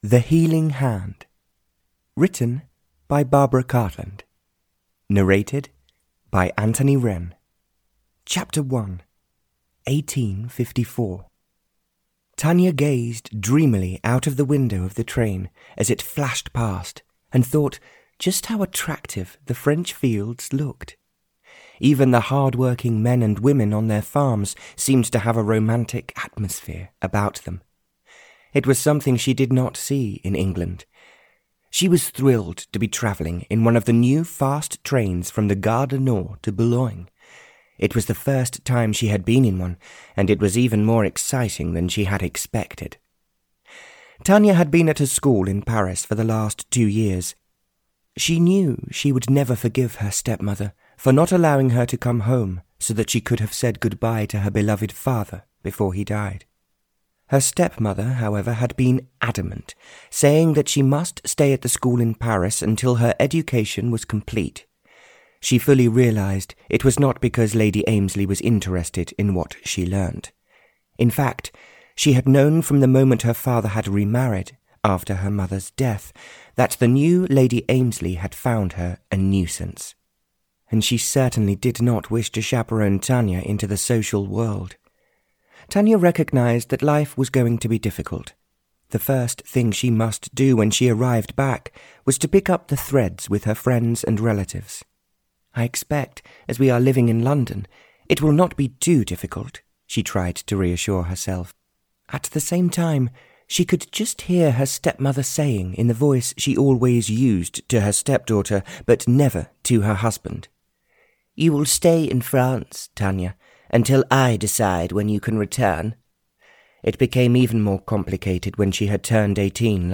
Audio knihaThe Healing Hand (Barbara Cartland's Pink Collection 80) (EN)
Ukázka z knihy